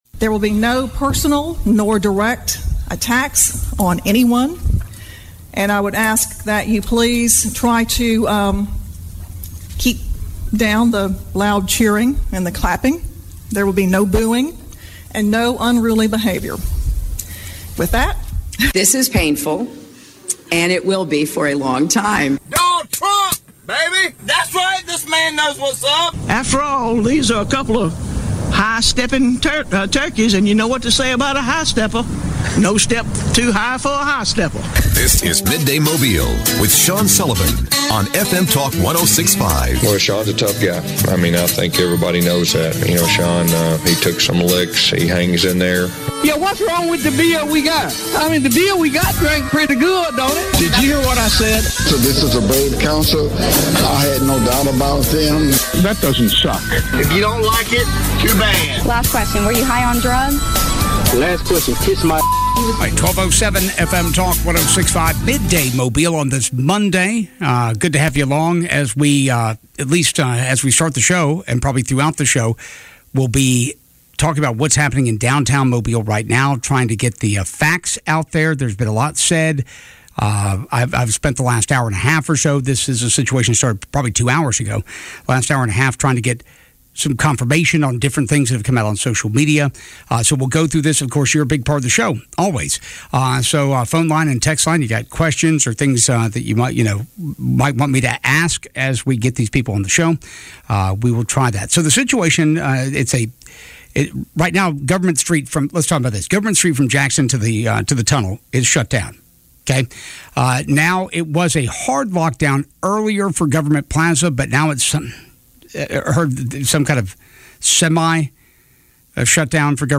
District Attorney Ashley Rich calls in